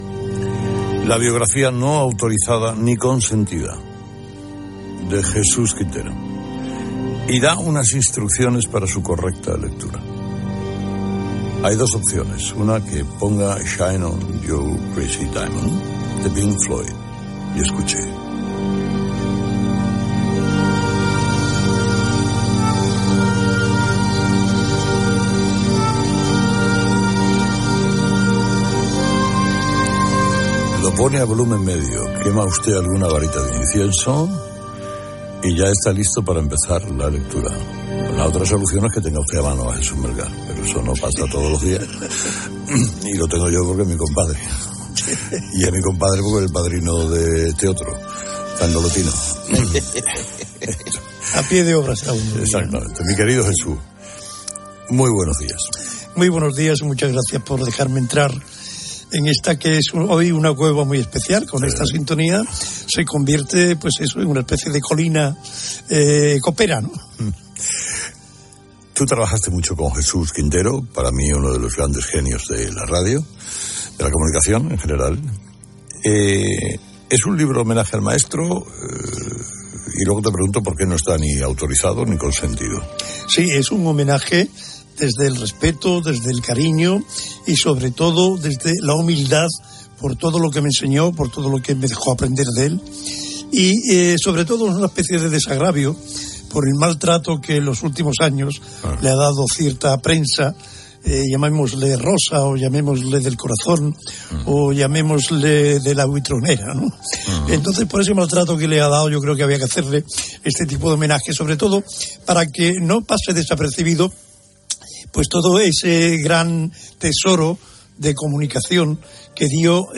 Entrevista
Gènere radiofònic Info-entreteniment